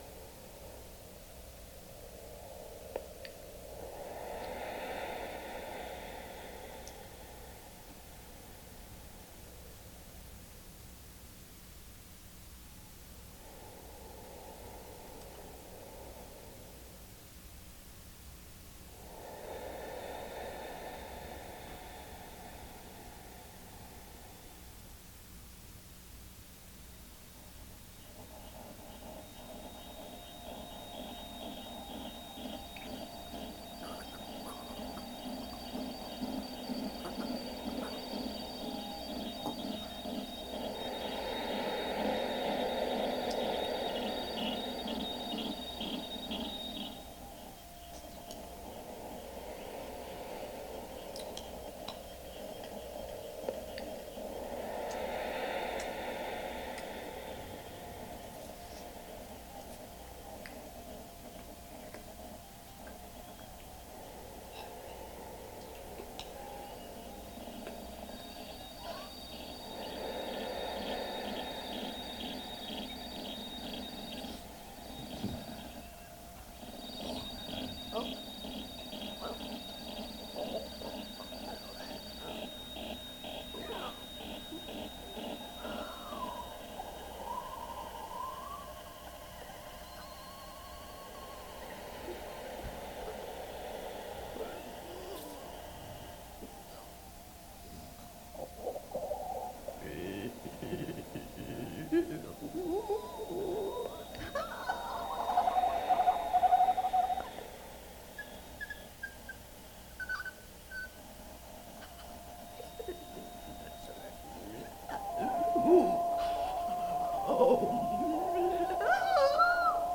Gorgeous Fever: the Radio Drama, 1994, New York, Roulette, concert performance, audio documentation of performance
live performance excerpt
format: audio cassette
was stripped away to situate the performer as sound effects artist who revealed to the audience the artifice of illusion.